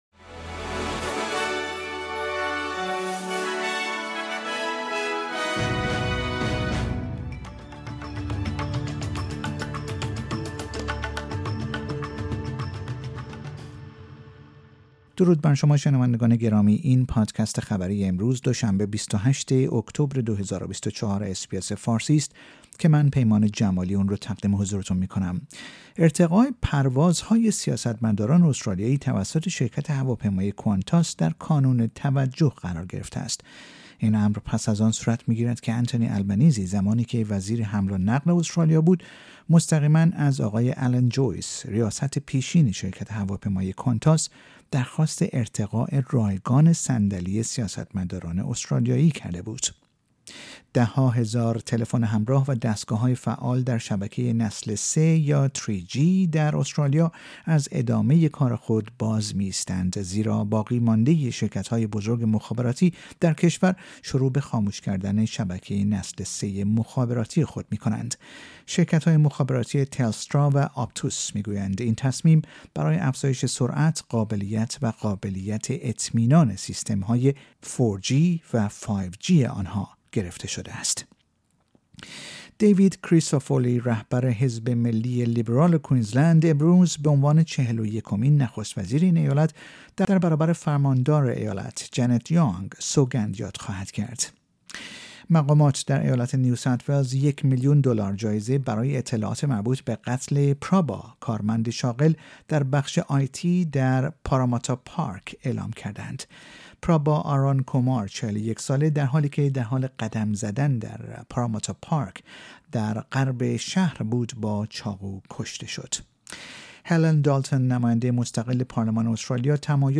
در این پادکست خبری مهمترین اخبار استرالیا در روز دوشنبه ۲۸ اکتبر ۲۰۲۴ ارائه شده است.